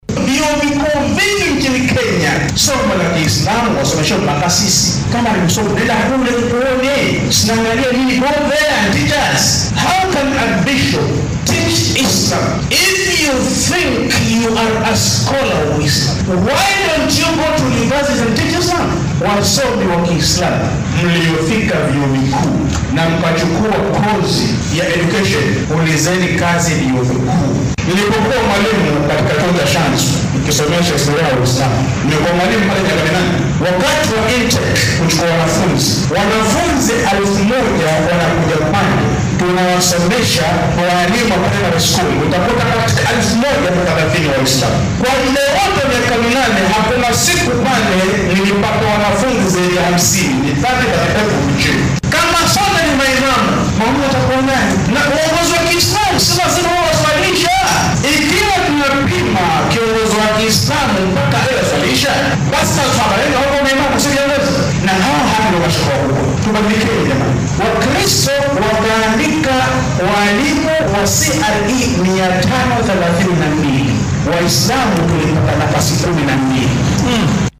Hadalkan ayuu ka jeediyay kulan ay culimaauddiinka ku yeesheen ismaamulka Mombasa.